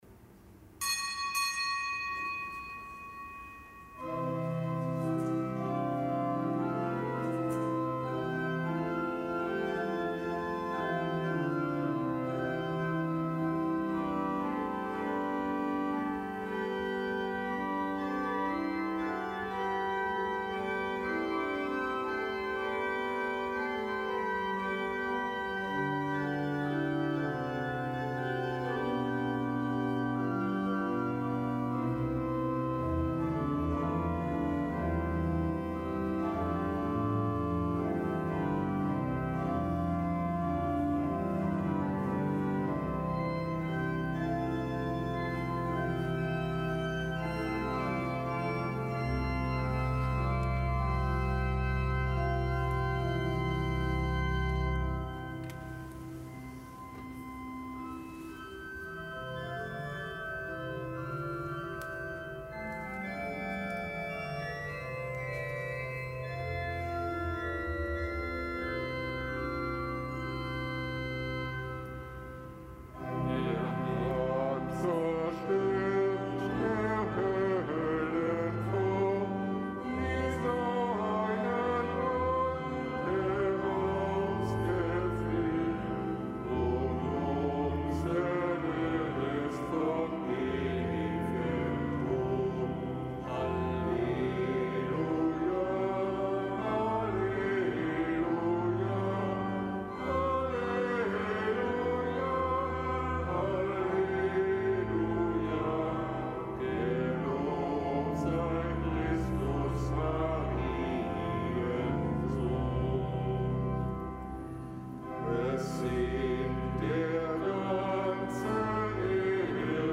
Kapitelsmesse aus dem Kölner Dom am Donnerstag der 2.